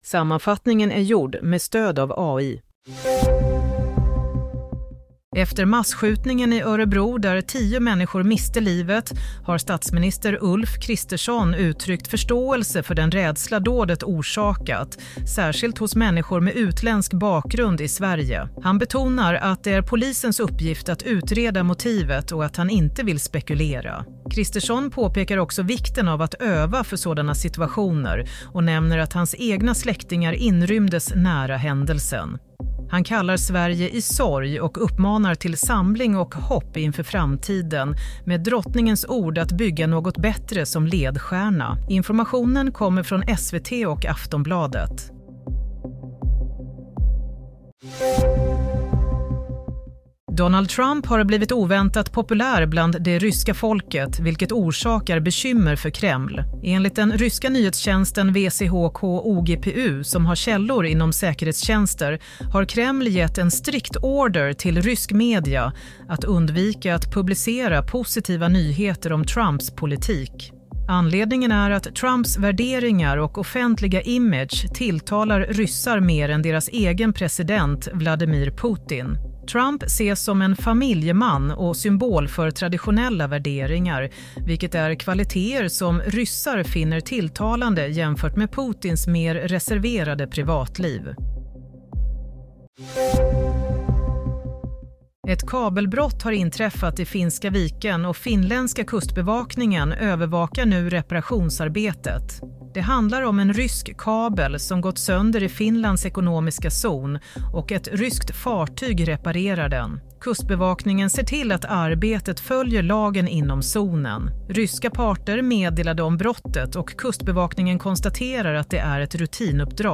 Nyhetssammanfattning - 8 februari 15:30
Sammanfattningen av följande nyheter är gjord med stöd av AI.